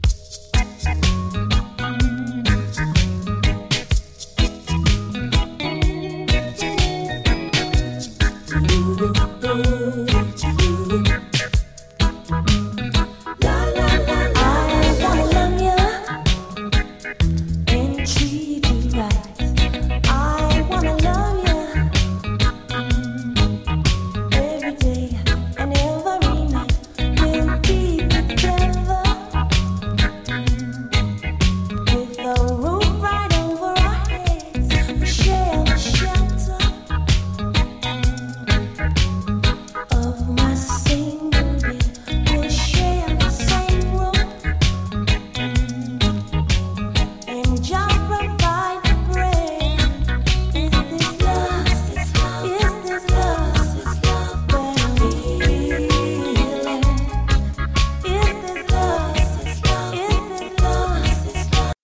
SOUL & FUNK & JAZZ & etc / REGGAE & DUB